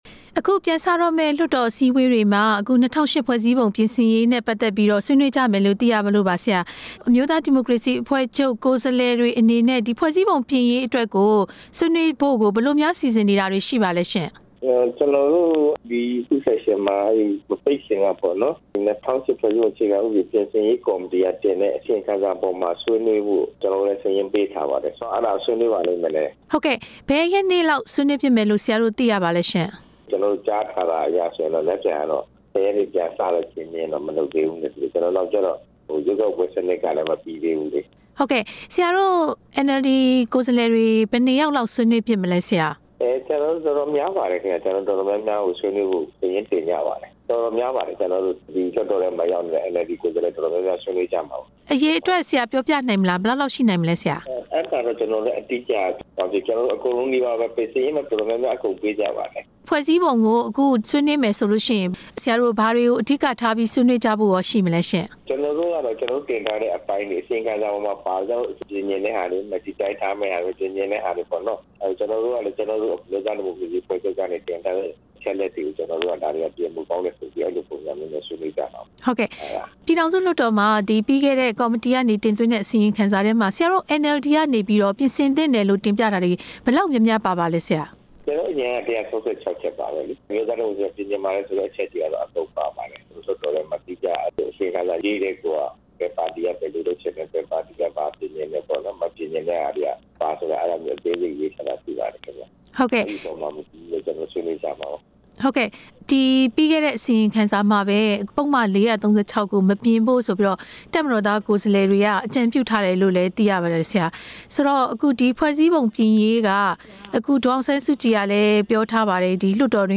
ပြည်သူ့လွှတ်တော်ကိုယ်စားလှယ် ဒေါက်တာဇော်မြင့်မောင်နဲ့ မေးမြန်းချက်